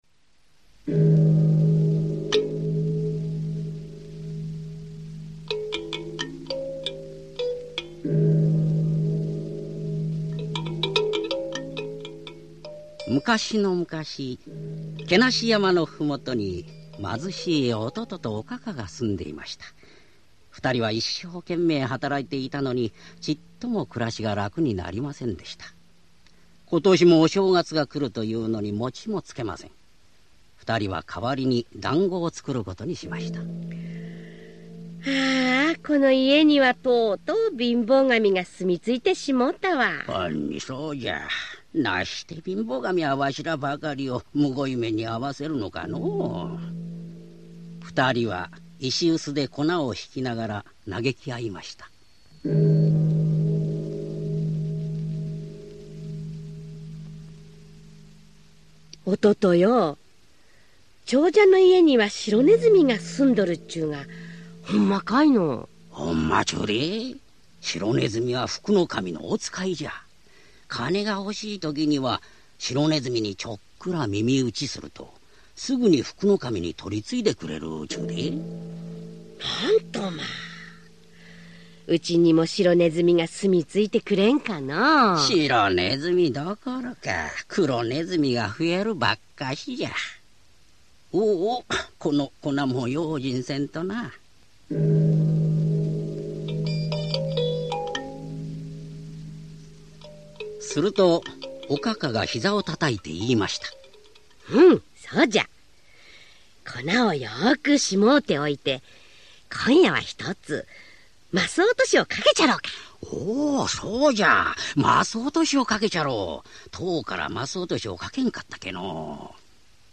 [オーディオブック] 福ねずみ